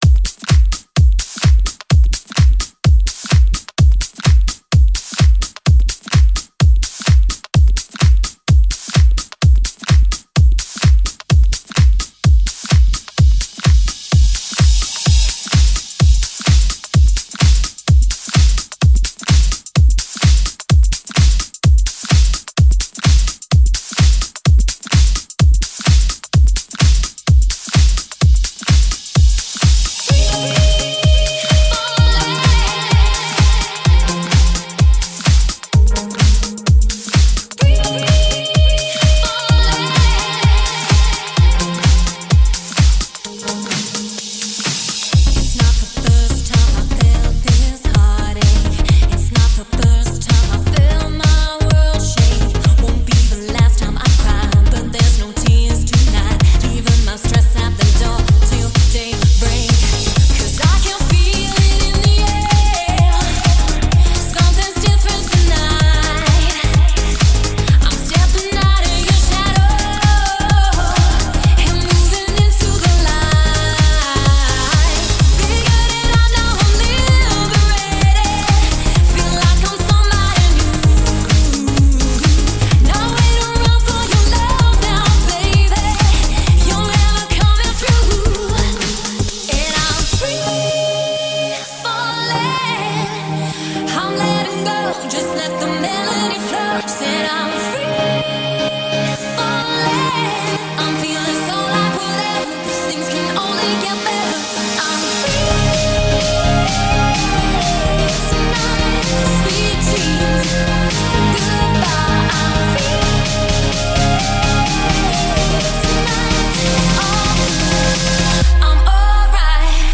Жанр: Dance music
новая клубная электро музыка